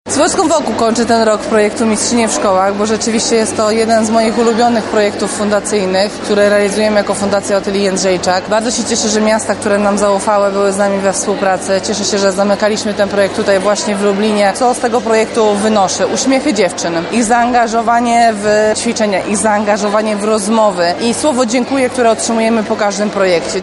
W czwartek w I Liceum Ogólnokształcącym w Lublinie im. Stanisława Staszica odbyła się wyjątkowa lekcja wychowania fizycznego.
MwSLublin_1026_OtyliaJędrzejczak_audio – mówi Otylia Jędrzejczak, autorka projektu.